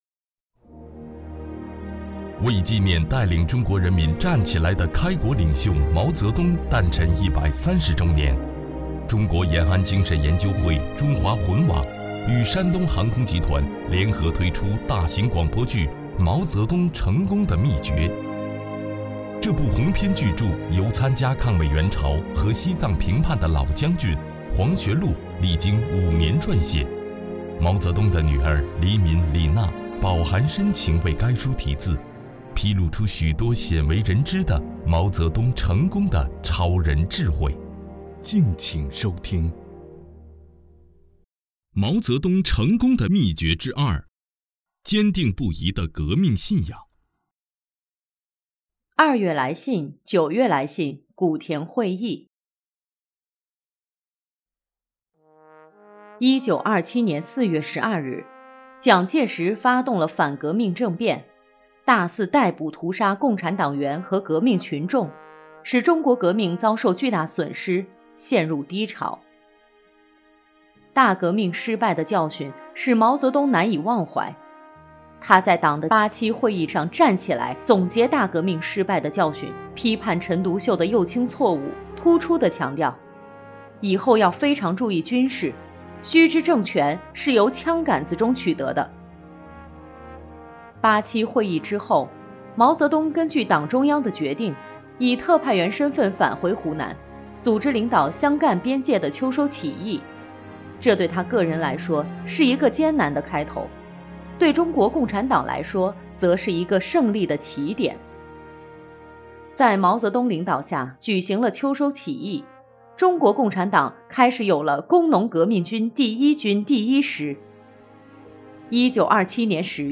为纪念带领中国人民站起来的开国领袖毛泽东诞辰130周年，中国延安精神研究会《中华魂》网与山东航空集团联合推出大型广播剧《毛泽东成功的秘诀》。